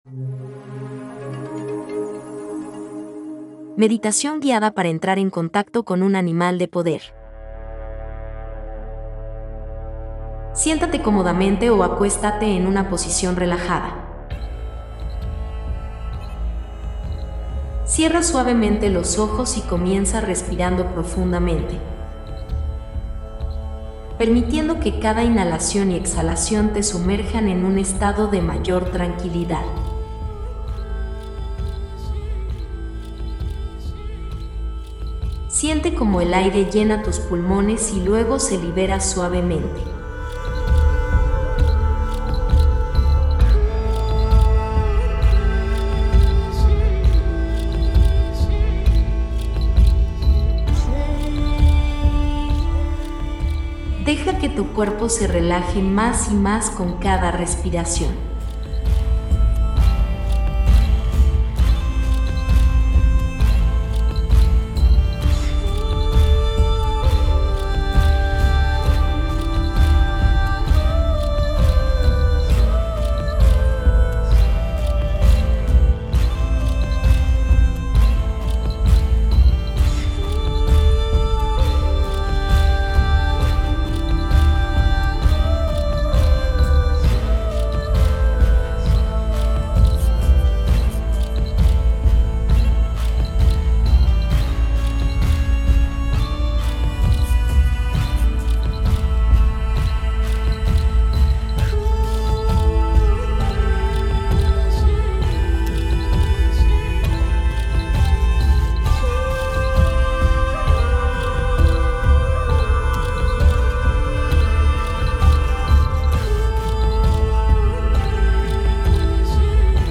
Elige si prefieres escuchar el audio de la meditación o ver el video